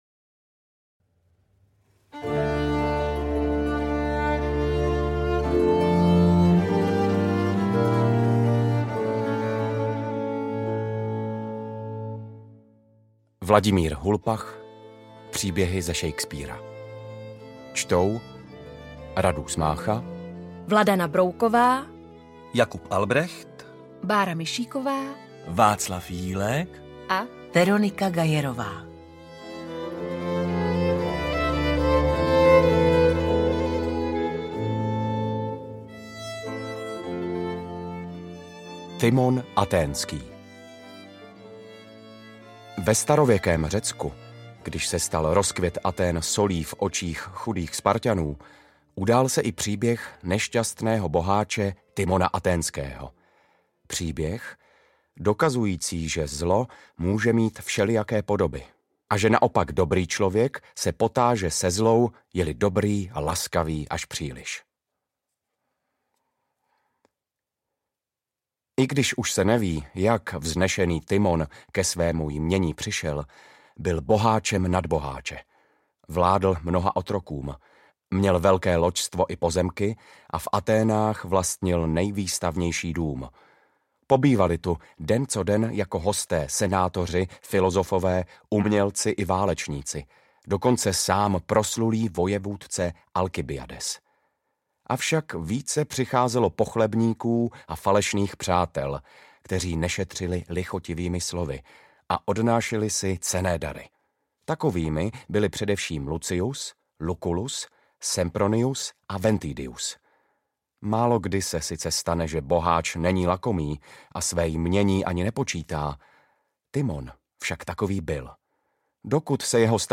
Audiobook
Read: Radúz Mácha